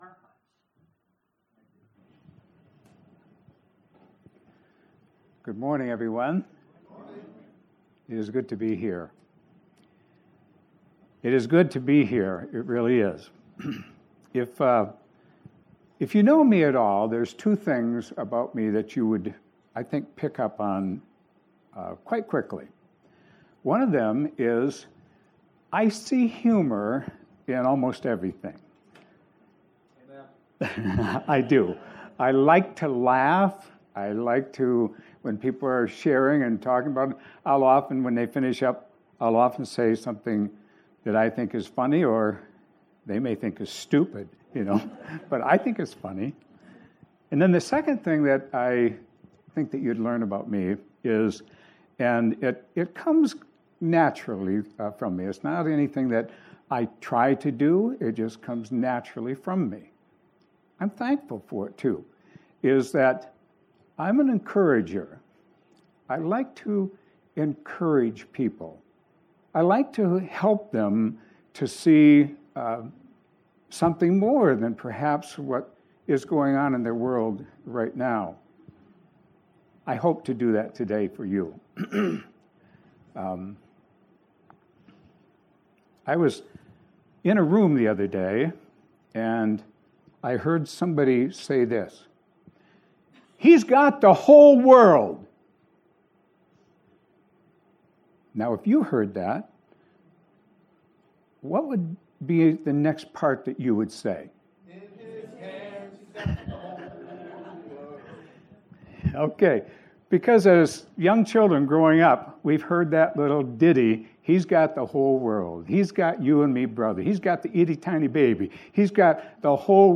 Guest Speaker June 23